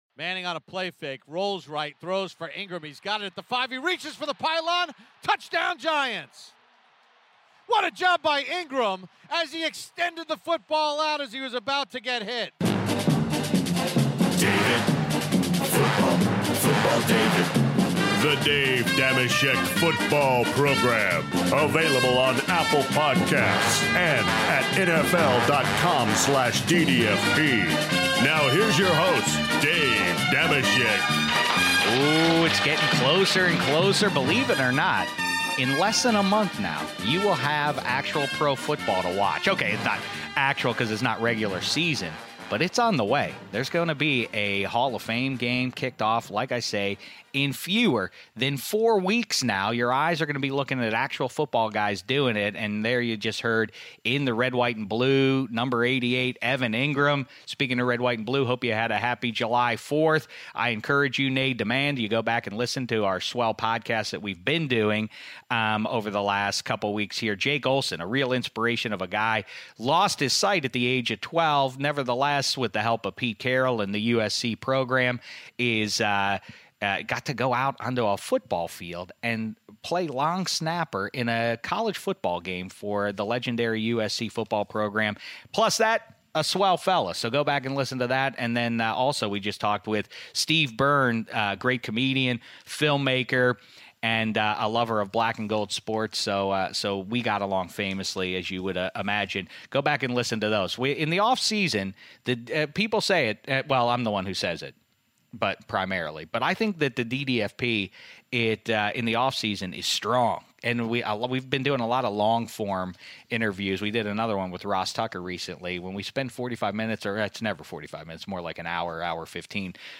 Dave Dameshek is joined by friend of the DDFP - New York Giants tight end Evan Engram! Evan calls in from his home in New Jersey before training camp to kibitz with Dave about Daniel Jones vs Eli Manning, Odell Beckham Jr.'s comments towards his old team, the Game of Thrones finale and a future Connect Four tournament (10:58).